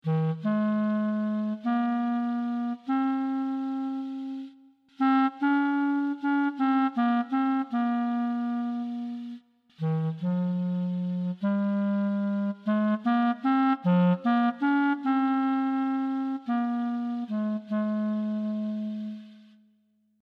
As a first approach, we have assumed that a MIDI-controlled digital clarinet synthesiser based on physical models is a sufficiently good instrument model.